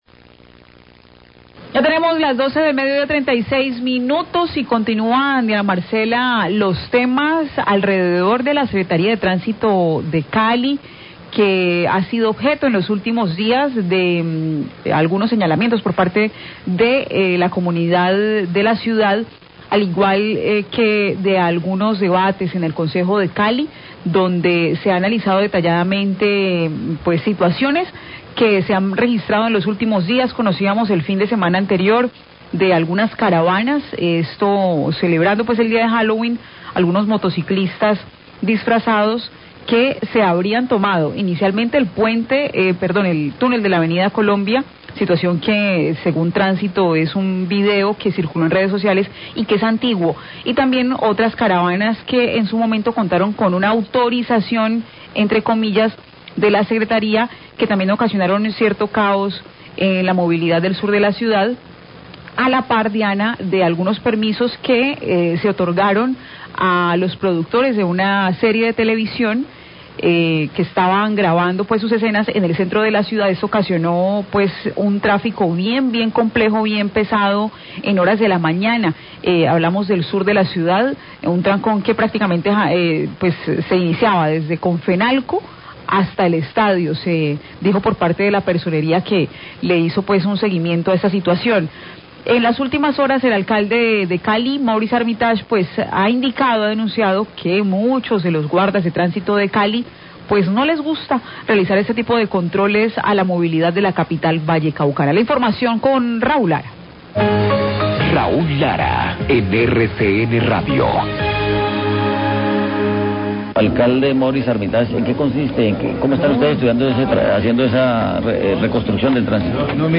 Radio
El alcalde Maurice Armitage se pronunció sobre la labor de los guardas de tránsito, afirmó que a muchos de ellos no les gusta salir a las calles a regular la movilidad.